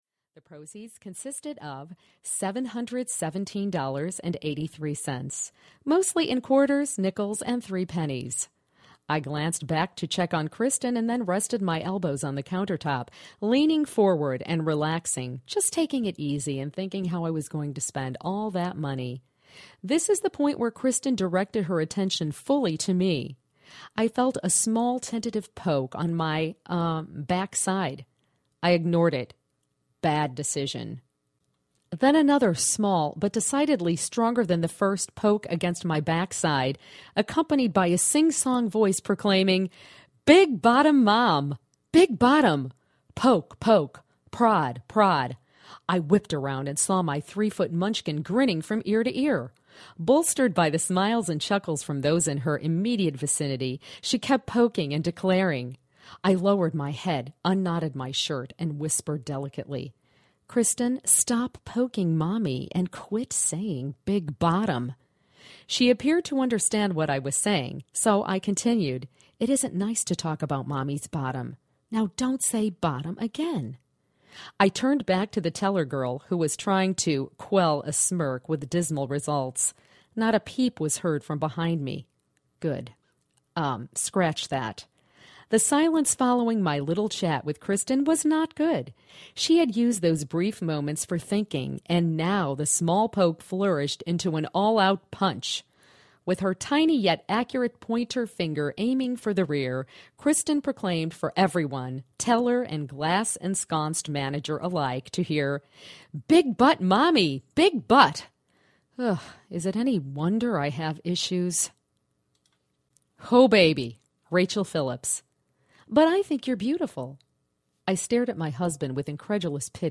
Help, I Can’t Stop Laughing! Audiobook